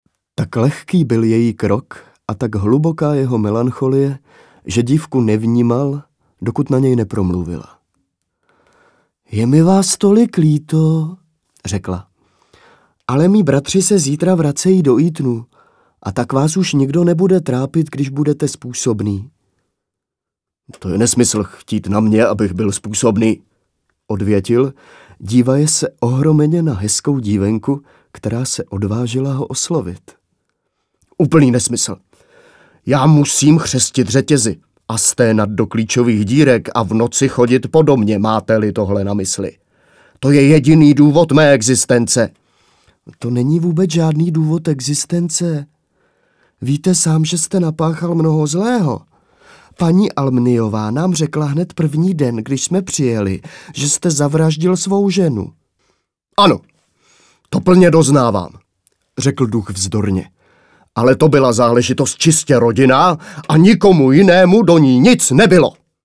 Audio knihy: